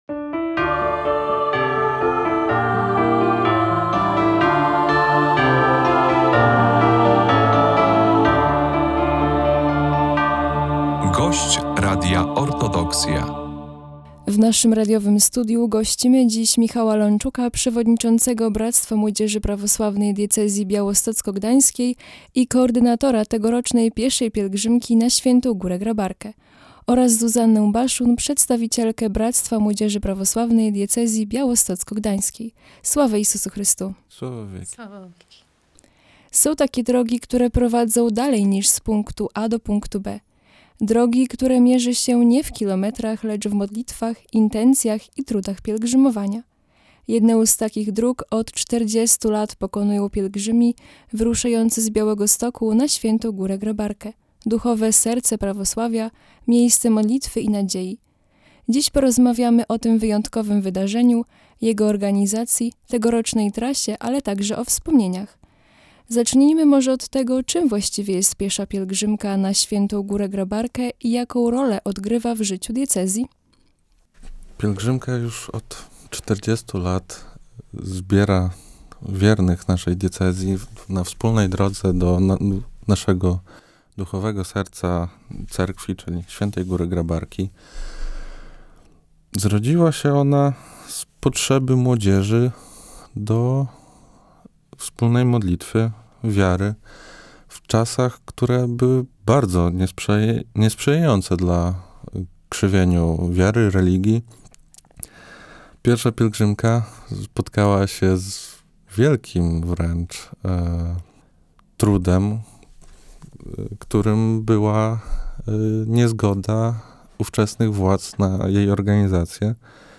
O tym wydarzeniu, jego organizacji, tegorocznej trasie i wyzwaniach, a także wspomnieniach, rozmawialiśmy z naszymi gośćmi